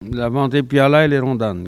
Maraîchin
Patois - archives